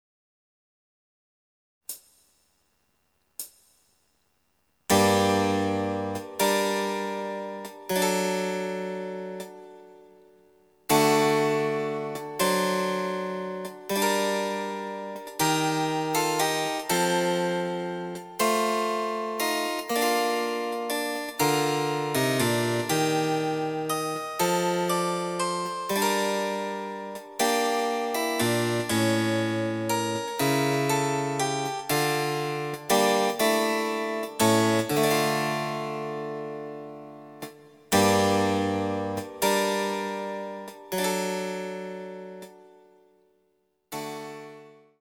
★フルートの名曲をチェンバロ伴奏つきで演奏できる、「チェンバロ伴奏ＣＤつき楽譜」です。
試聴ファイル（伴奏）
デジタルサンプリング音源使用
※フルート奏者による演奏例は収録されていません。